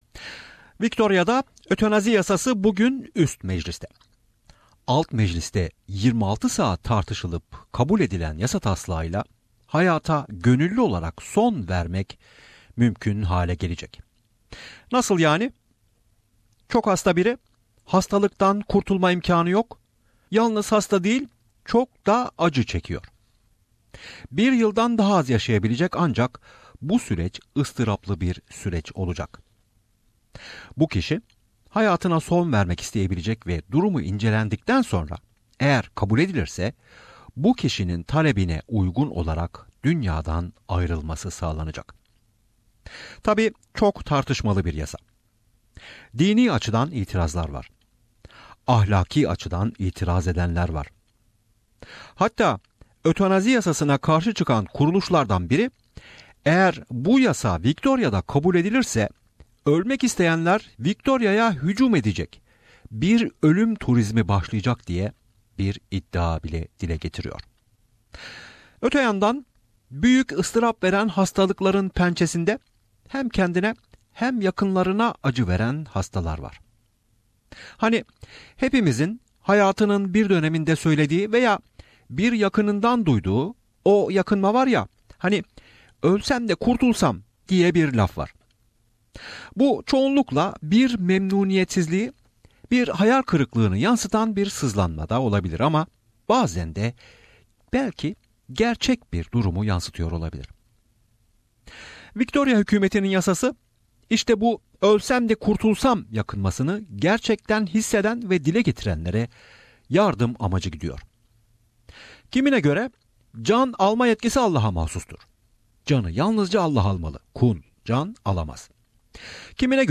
Bu bölümde dinleyicilerimizin konuya ilişkin telefon mesajları da yer alıyor.